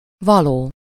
Ääntäminen
IPA : [ˈsuː.təbl]